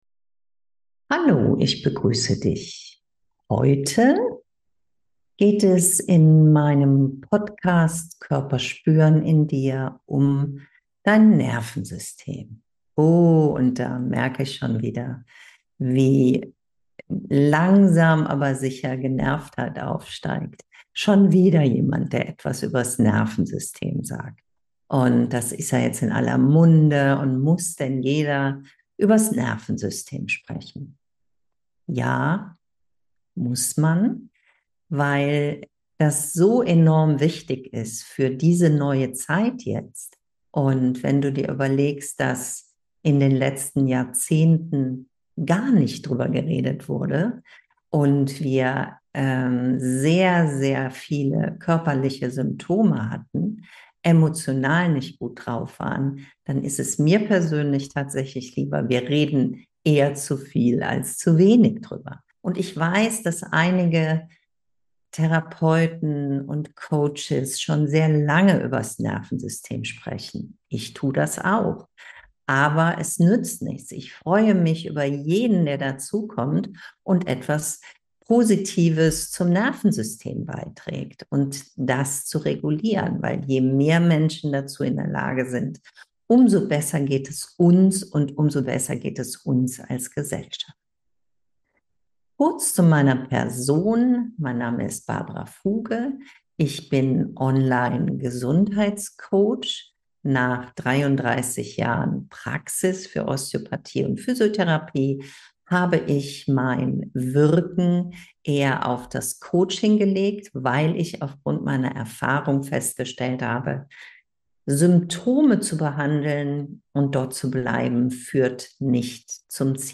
Mit dabei: Eine geführte Mini-Meditation & einfache Wahrnehmungsübungen für deinen Alltag.